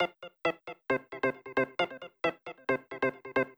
GAR Top Organ Riff F-E-C-Eb.wav